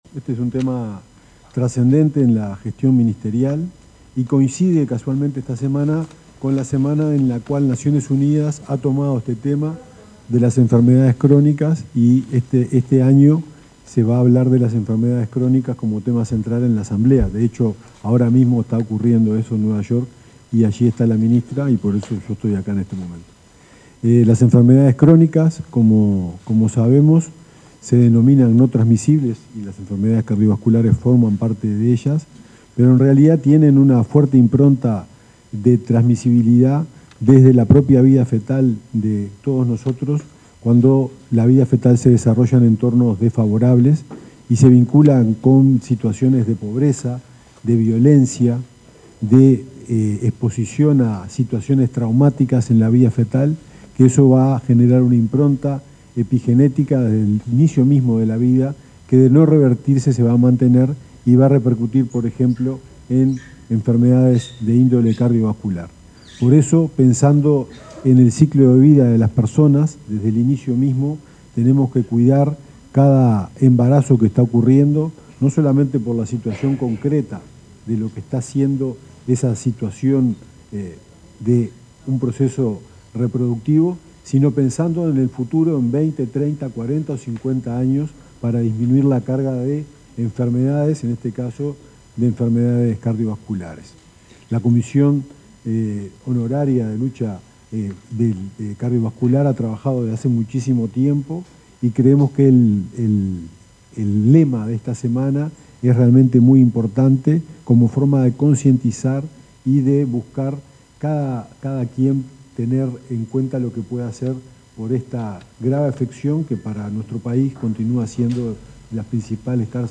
Palabras del subsecretario de Salud Pública, Lionel Briozzo, y la directora general de Salud, Fernanda Nozar
Palabras del subsecretario de Salud Pública, Lionel Briozzo, y la directora general de Salud, Fernanda Nozar 22/09/2025 Compartir Facebook X Copiar enlace WhatsApp LinkedIn Durante la ceremonia de lanzamiento de la 34.ª Semana del Corazón, el subsecretario de Salud Pública, Lionel Briozzo, y la directora general de Salud, Fernanda Nozar, expusieron sobre las políticas públicas que se desarrolla en materia de prevención.